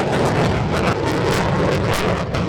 thrust.wav